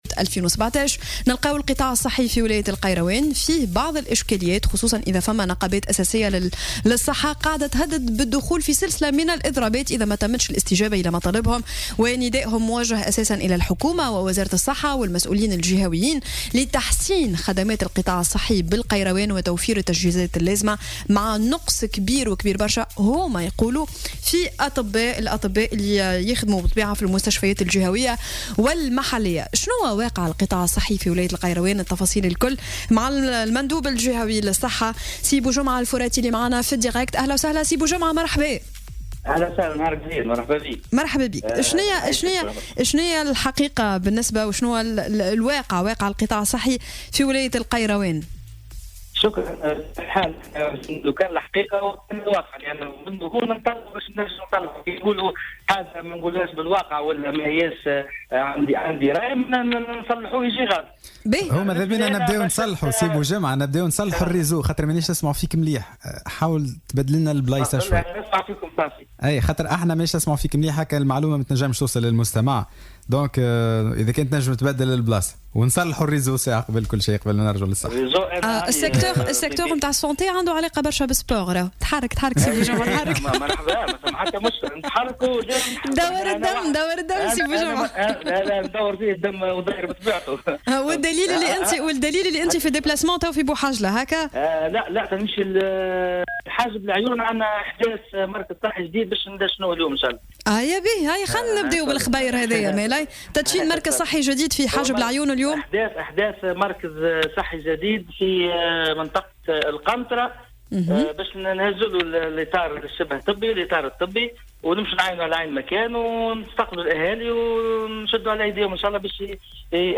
وأكد في المقابل أنه تم تسجيل نقص في عدد الاطباء الذين يقدر عددهم 202 طبيب عام ومتخصص، في تصريحات لـ "الجوهرة أف أم" في برنامج "صباح الورد".